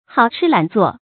hào chī lǎn zuò
好吃懒做发音
成语注音 ㄏㄠˇ ㄔㄧ ㄌㄢˇ ㄗㄨㄛˋ
成语正音 好，不能读作“hǎo”；懒，不能读作“lài”。